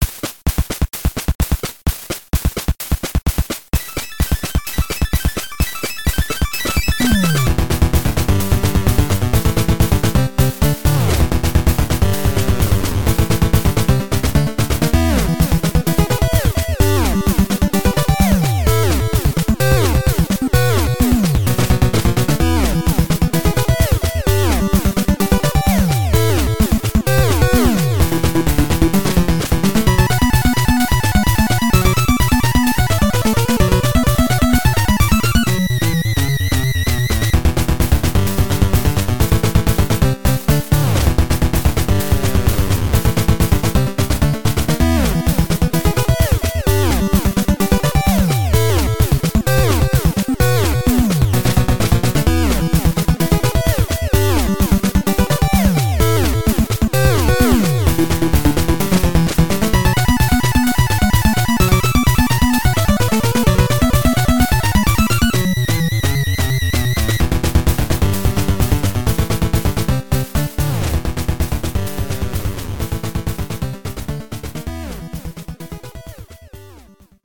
Tags: Classic Video Games NES Game Music Sounds Clips